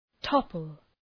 {‘tɒpəl}